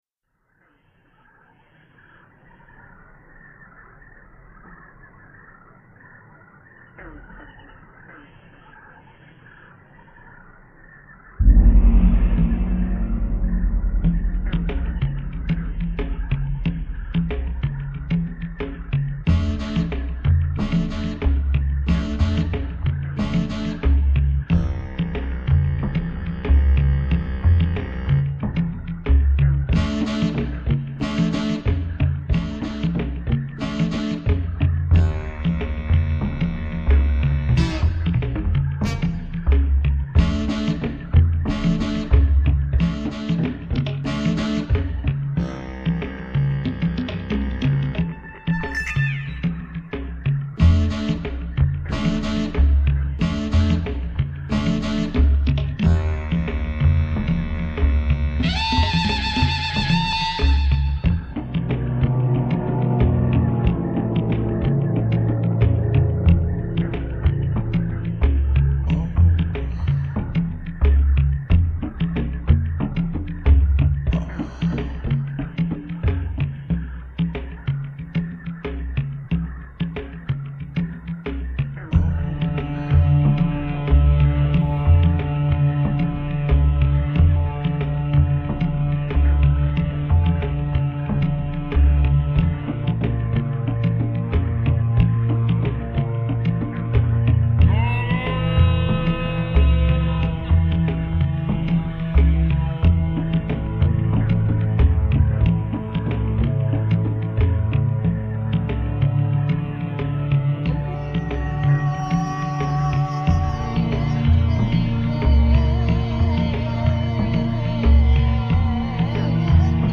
upbeat electronic pieces
sampling keyboards and synthesizer